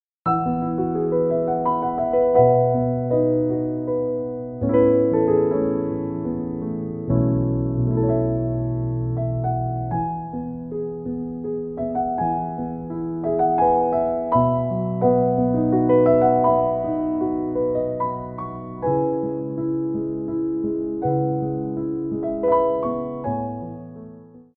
Port de Bras 1
3/4 (8x8)